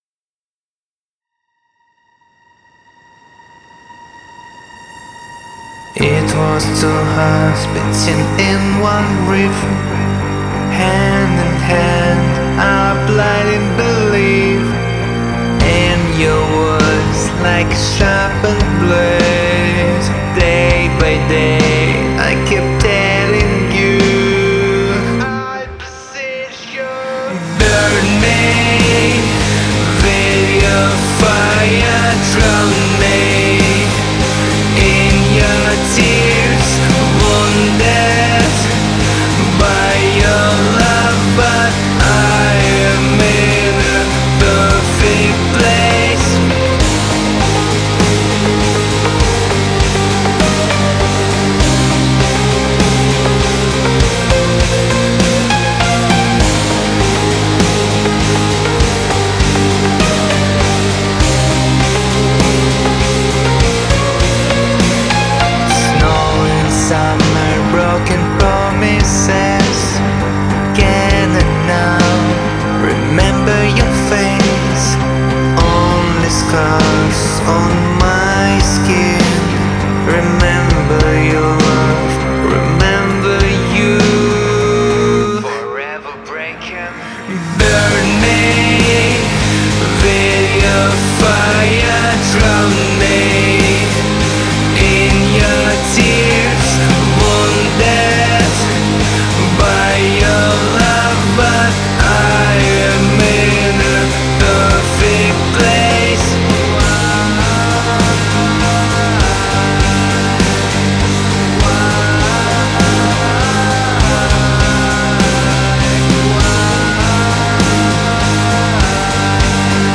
versão remasterizada